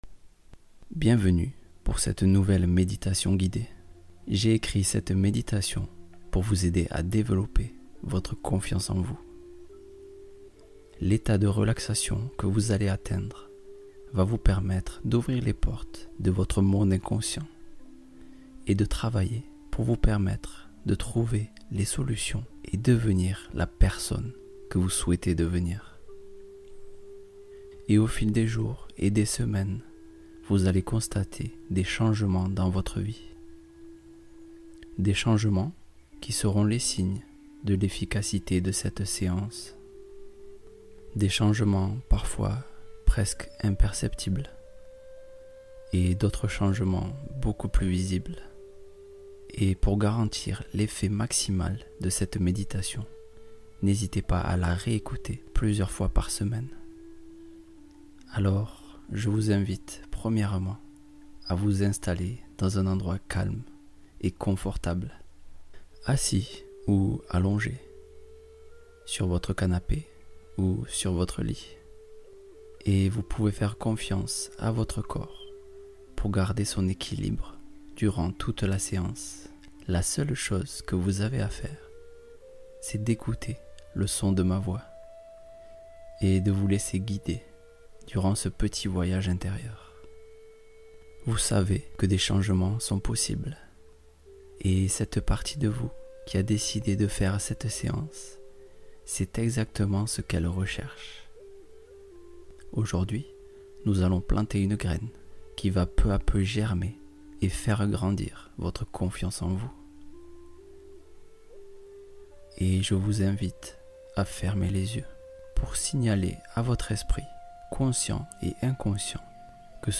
Confiance totale : méditation guidée intensément puissante